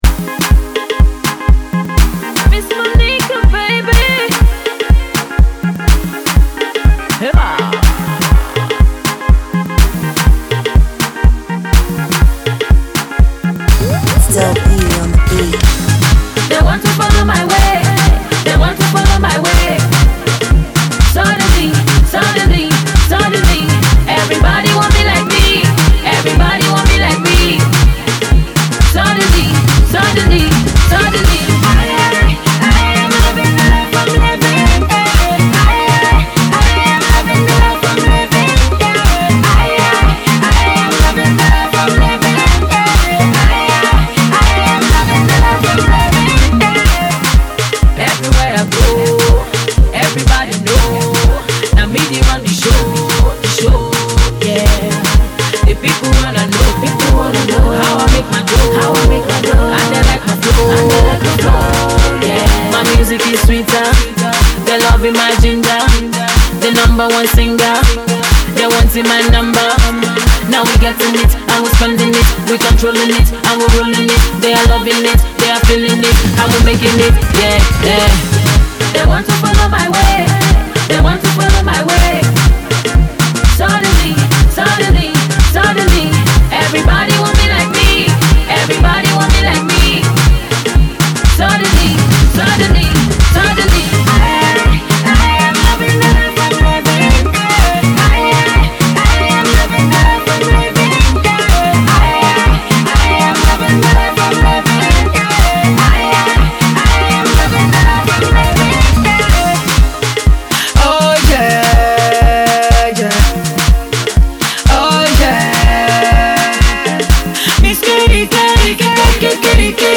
mid-tempo feel good jam